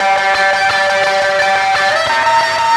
Track 02 - Guitar Lead 03.wav